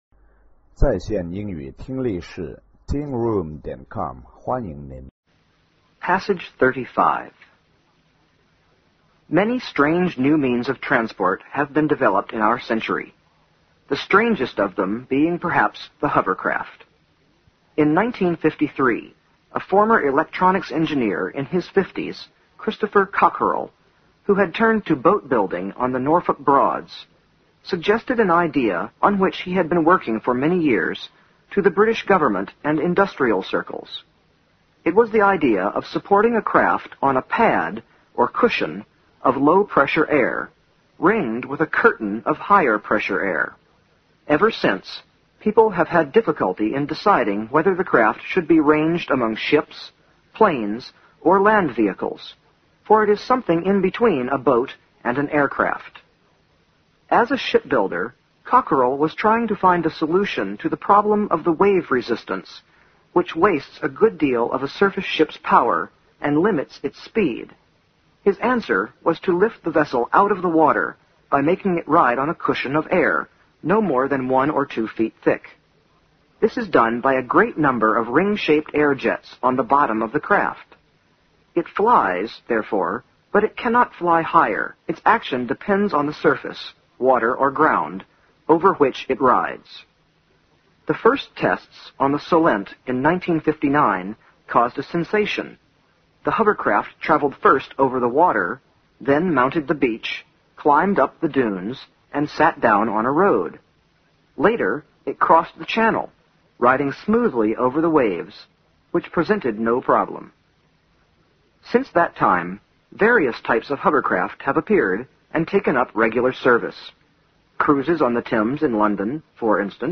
新概念英语85年上外美音版第四册 第35课 听力文件下载—在线英语听力室